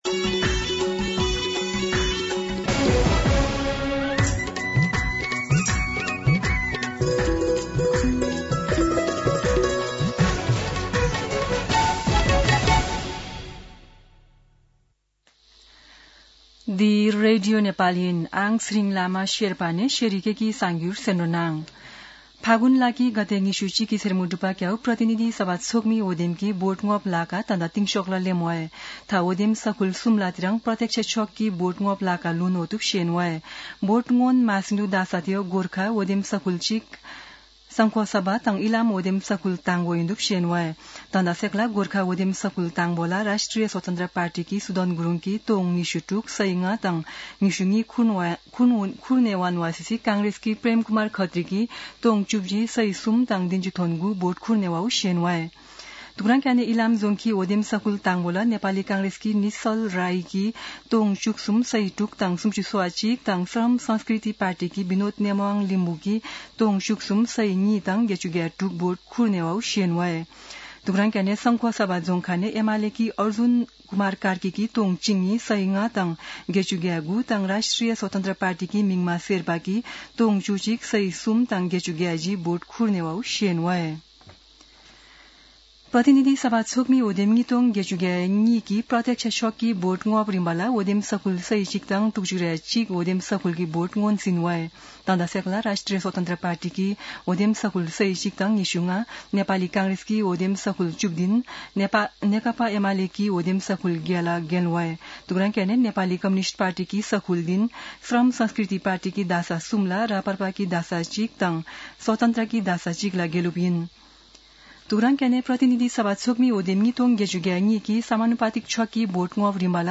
शेर्पा भाषाको समाचार : २५ फागुन , २०८२
Sherpa-News.mp3